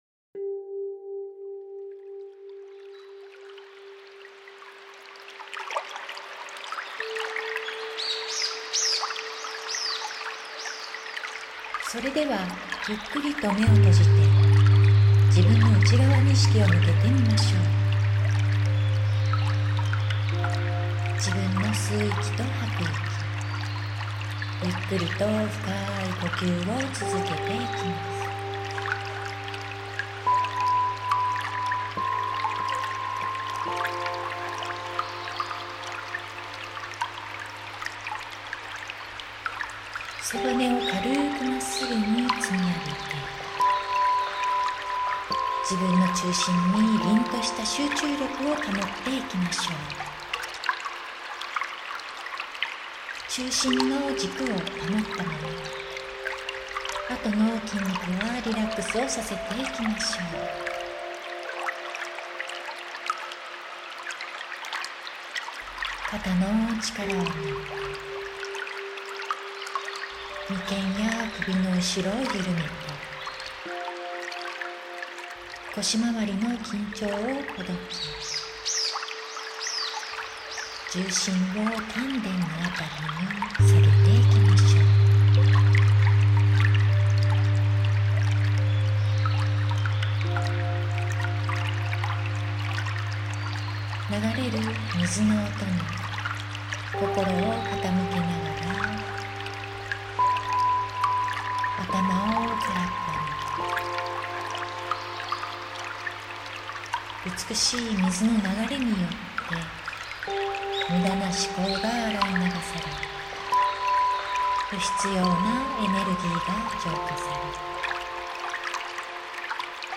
約12分の瞑想音源です。
誘導の音声は最小限にしています。 ただ水の流れる音に耳を方向け「無・無限・ゼロ」に戻るリラックス時間をお楽しみください。
水の浄化瞑想.mp3